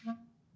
Clarinet
DCClar_stac_A#2_v1_rr1_sum.wav